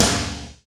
Index of /90_sSampleCDs/Roland L-CDX-01/KIT_Drum Kits 5/KIT_Induced Kit